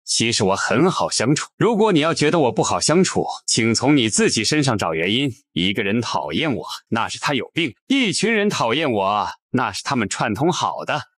为病毒式内容打造的搞笑戏剧性AI配音
生成专为表情包、戏仿作品和引人入胜的社交媒体娱乐内容设计的夸张、喜剧和戏剧性配音。
文本转语音
戏剧性语调
喜剧节奏
专为需要额外夸张情感冲击的创作者设计，这款声音可以从声泪俱下的恳求无缝切换到狂躁的兴奋。
我们的AI擅长夸张表演的艺术，能够以肥皂剧和动漫中常见的强烈、戏剧化的风格来演绎台词。